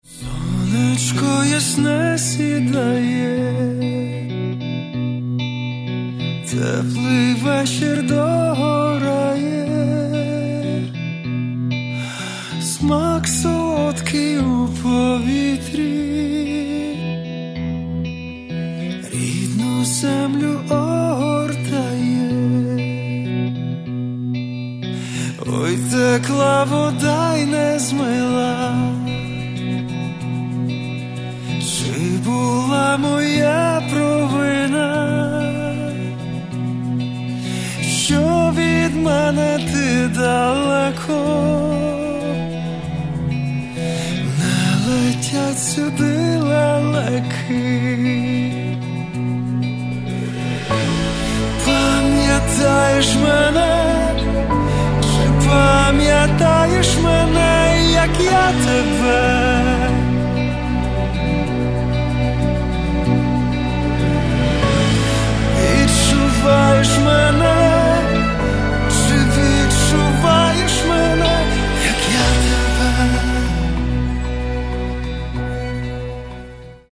Catalogue -> Rock & Alternative -> Light Rock